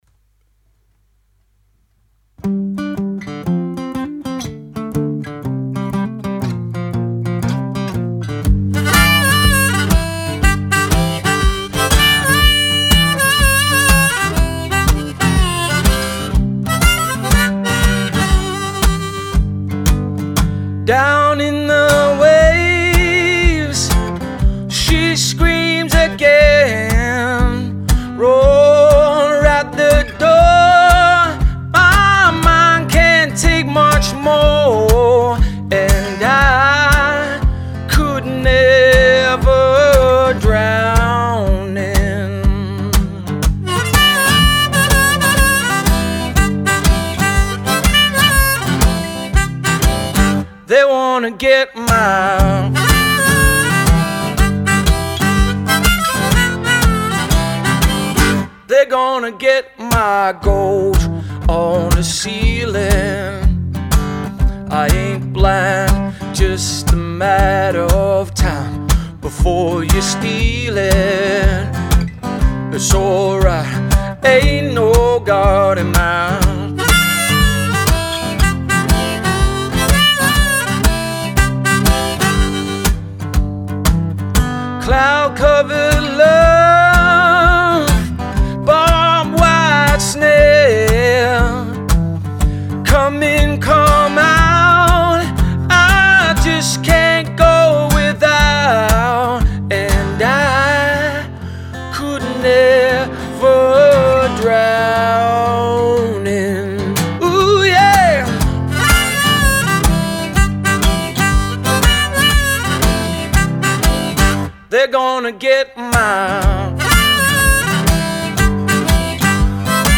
Vocals | Guitar | DJ | Harmonica